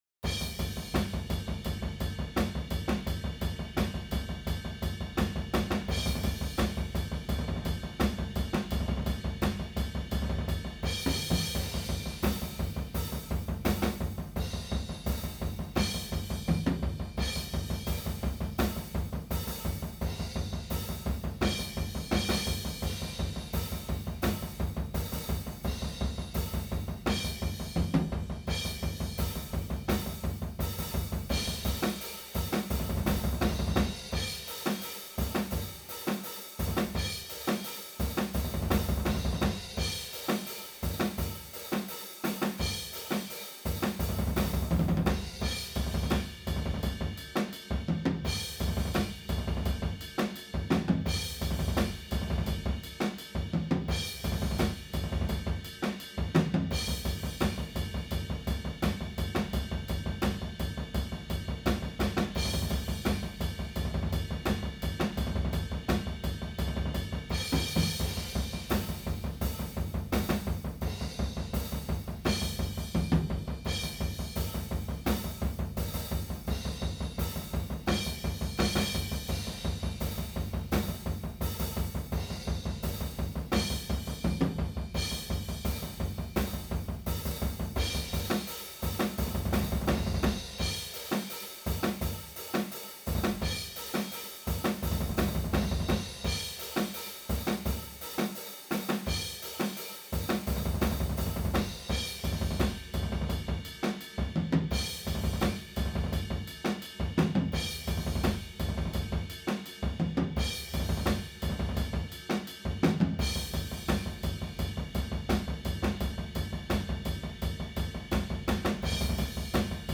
humanized-AmbL-0.wav